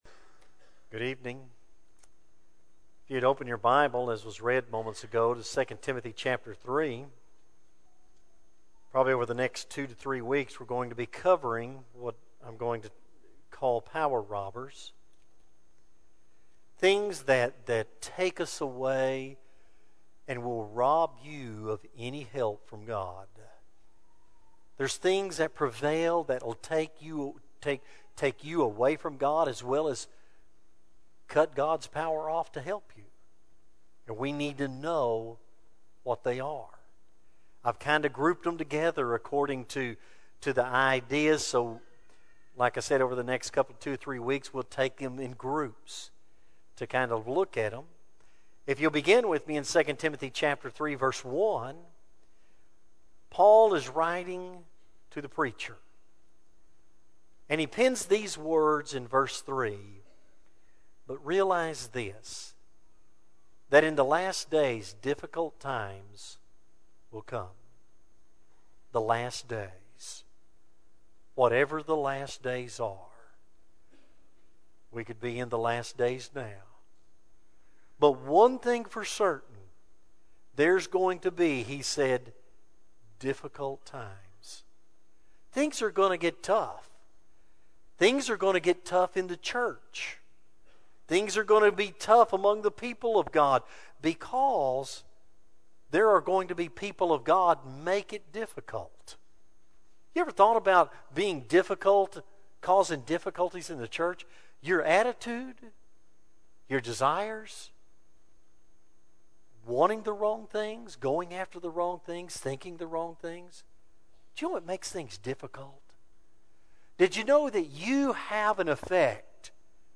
2025 Sermons CURRENT